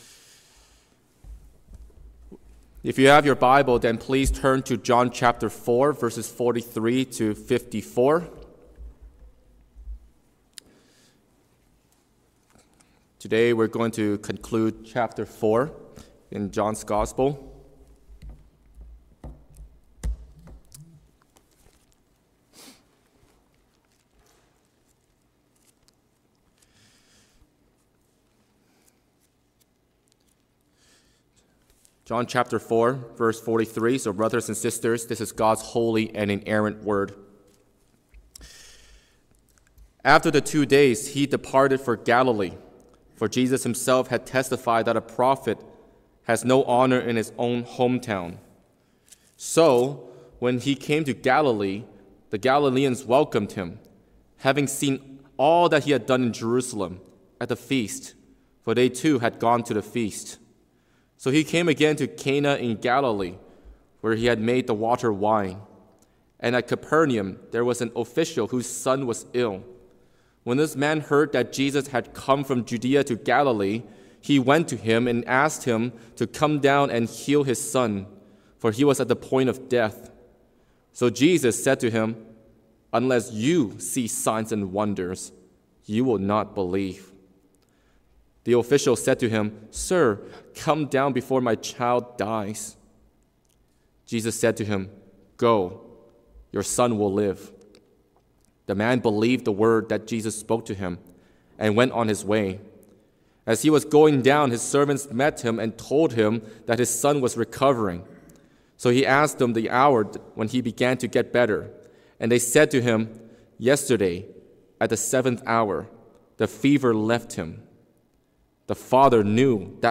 Sermons | Oakridge Baptist Church